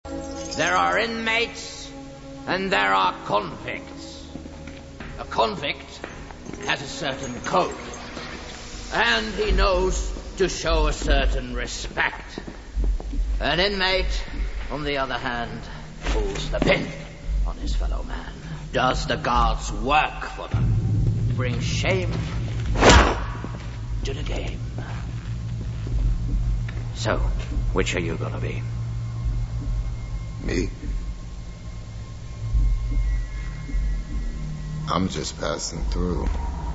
Звуковые дорожки:  Английская — оригинал, DD5.1, 448 Кбит/с.
Очень приличный звук, достаточно глубокий, с очень разборчивой речью персонажей.
оригинал (144 КБ), обычный перевод на русский (147 КБ) и